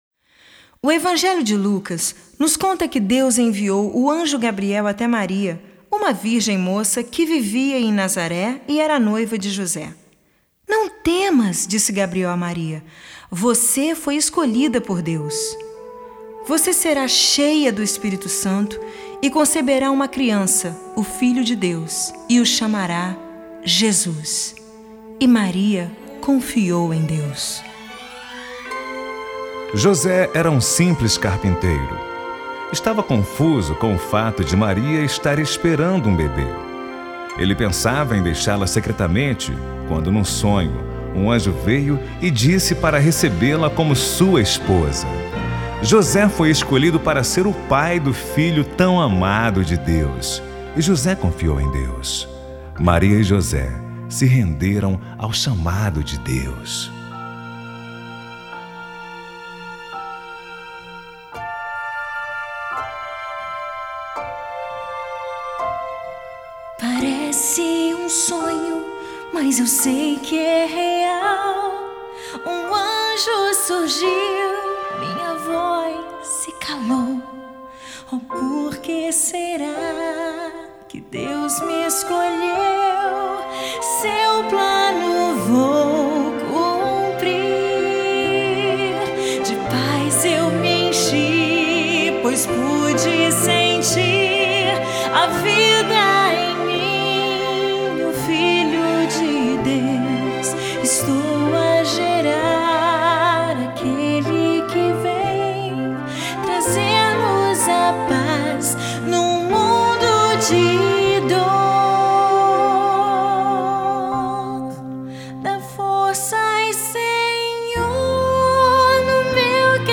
sua próxima cantata de Natal.